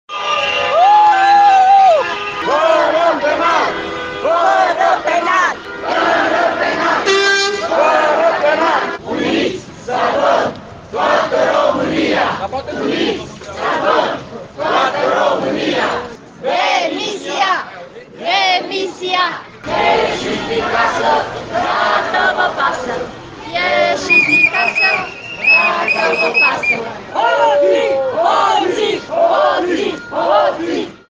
Circa 300 de persoane au ieşit ieri şi în centrul municipiul Tg.Mureş pentru a-şi manifesta nemulţumirea faţă de Guvern, dar şi solidaritatea faţă de participanţii din diaspora. Protestul s-a derulat pașnic, cu pancarte în fața Prefecturii jud. Mureş, participanţii scandând lozinci de încurajare a populaţiei să participe la protest şi antiguvernamentale:
stiri-11-aug-vox-protest.mp3